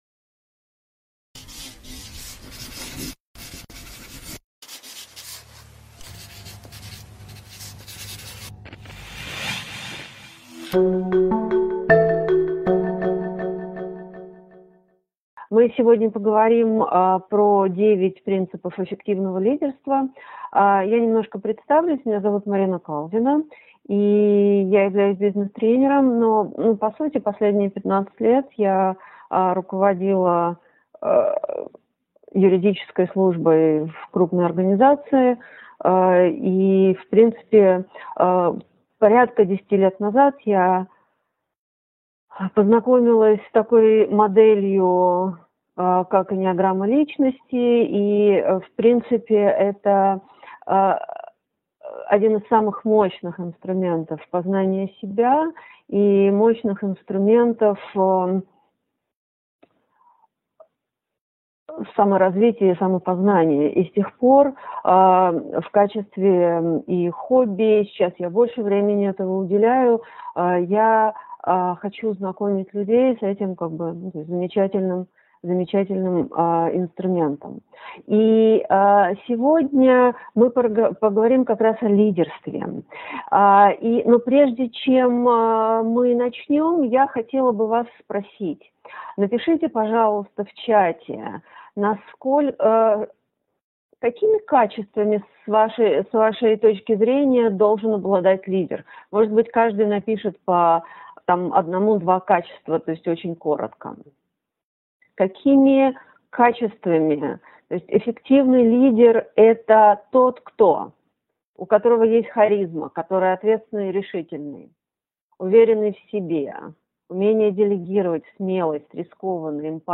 Аудиокнига 9 принципов эффективного лидерства | Библиотека аудиокниг